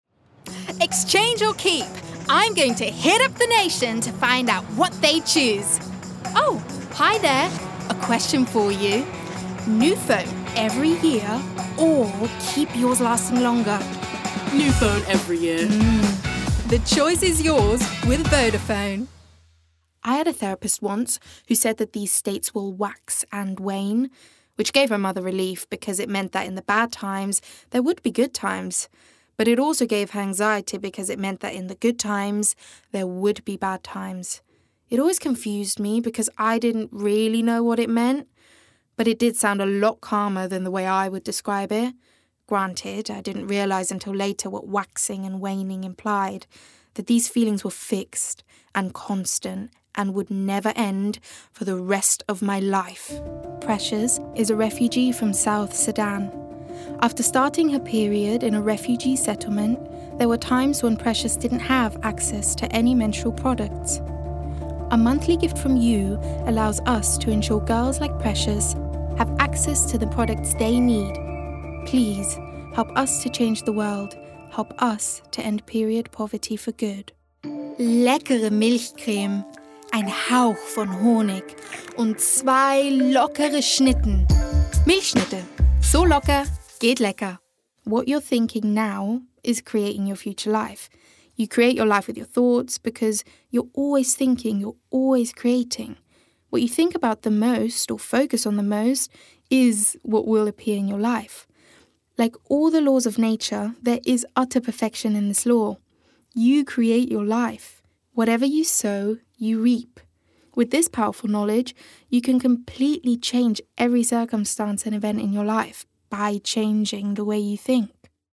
Native voice:
Austrian
Voicereel: